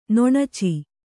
♪ noṇaci